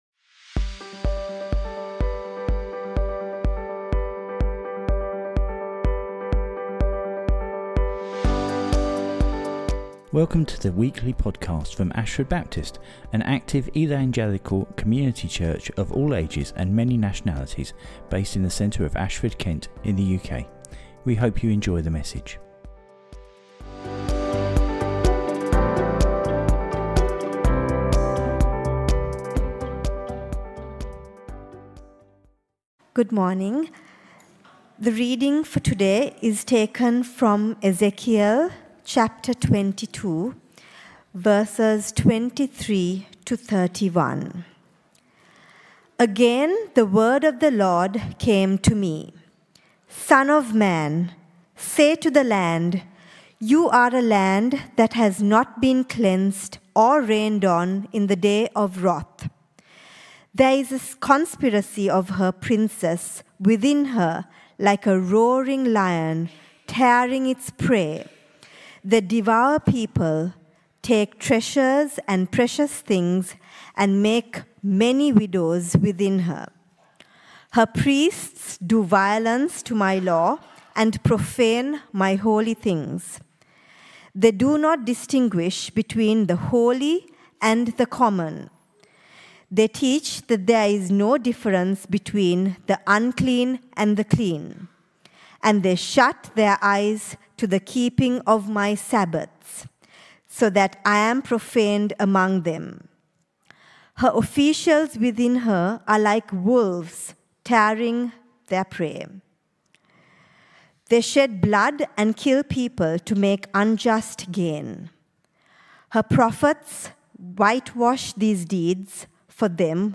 Apologies for the drop in audio quality at the end of the recording.